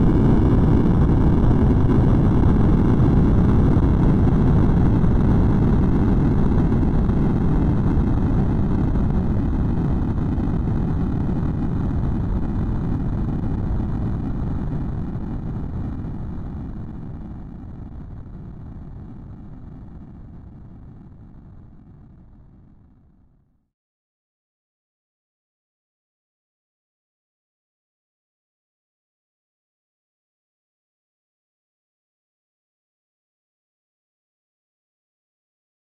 描述：复古视频游戏8位爆炸
Tag: 复古 爆炸8位 视频游戏